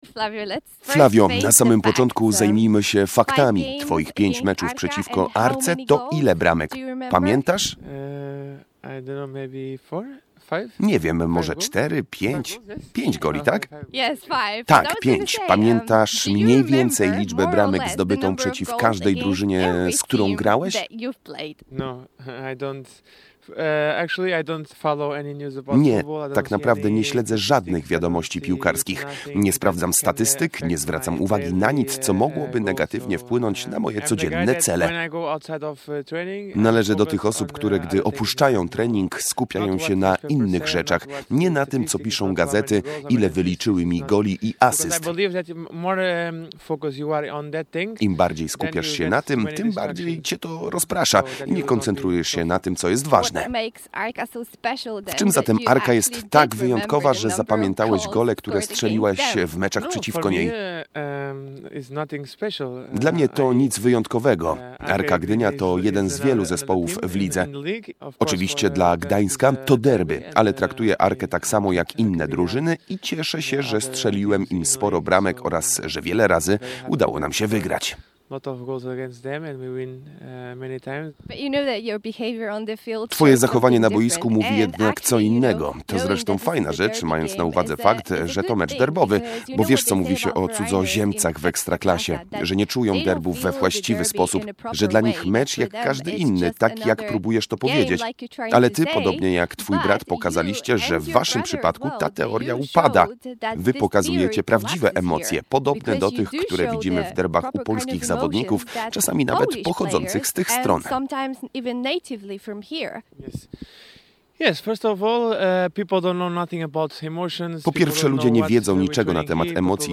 – Prawdopodobnie tak! Spodziewam się jej i mam taką nadzieję – mówił do mikrofonu Radia Gdańsk portugalski napastnik Lechii.